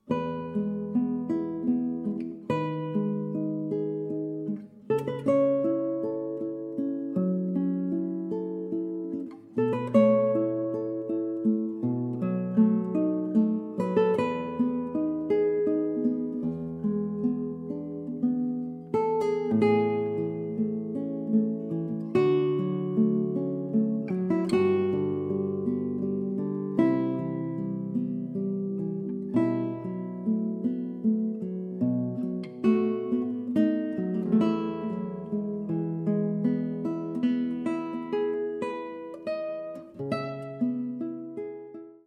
Solo Guitar